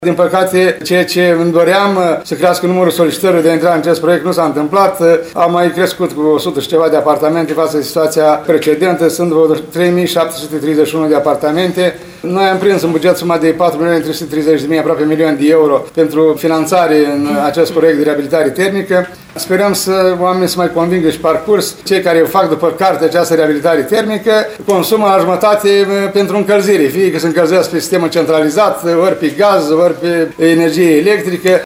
La ședința de astăzi cu șefii asociațiilor de proprietari, primarul ION LUNGU a precizat că – în ultimele 4 luni – numărul apartamentelor care ar putea fi incluse în acest proiect a crescut cu numai 100, ajungând la 3.700.